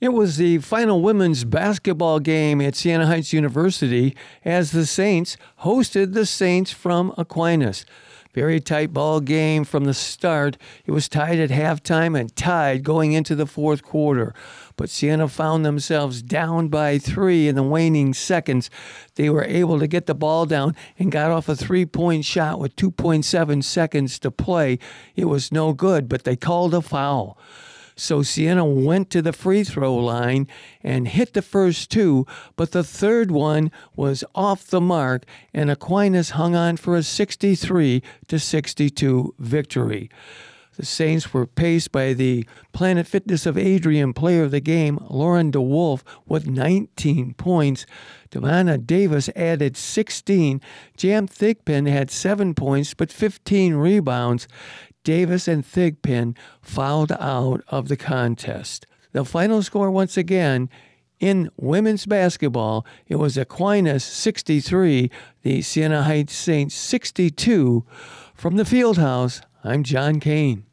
called the women’s game…